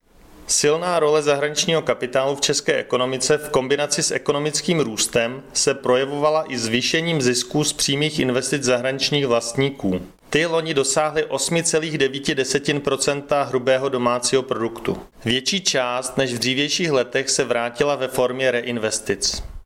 Vyjádření Marka Rojíčka, předsedy ČSÚ, soubor ve formátu MP3, 891.88 kB